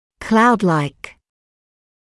[‘klaudlaɪk][‘клаудлайк]облакоподобный, похожий на облако